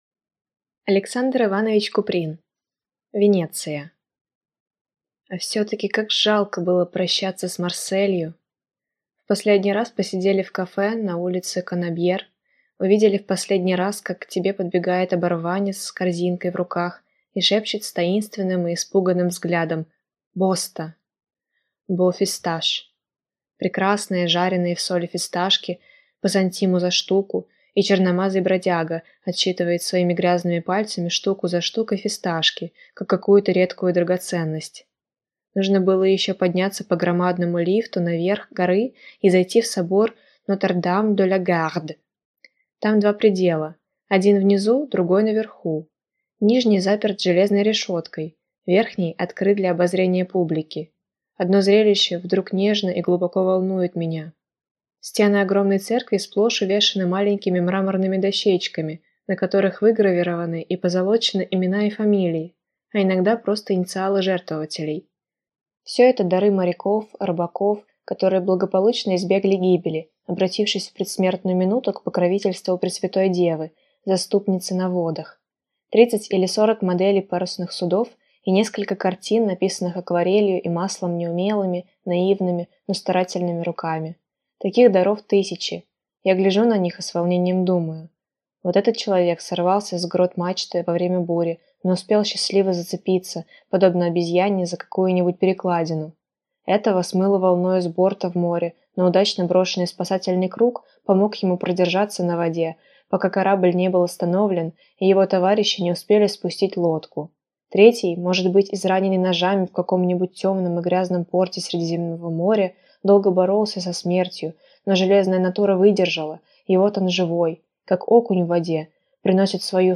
Аудиокнига Венеция | Библиотека аудиокниг